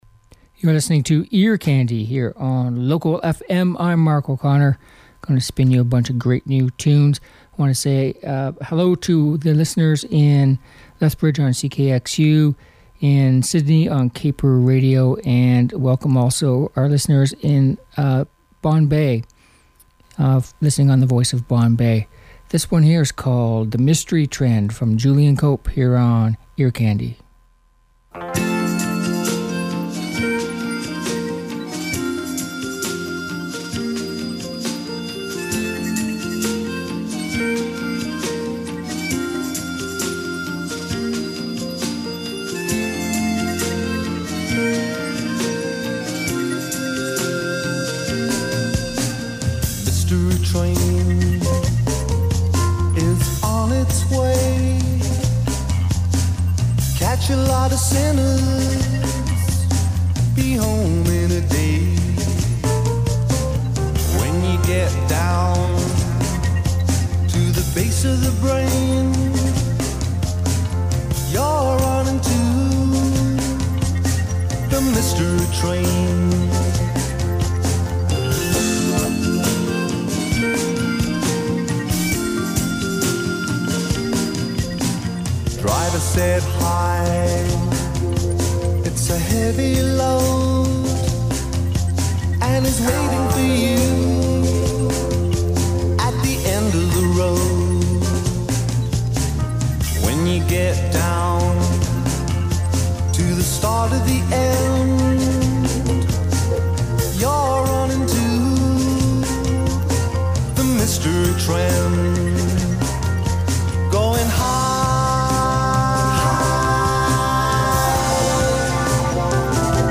Uptempo Pop and Rock Songs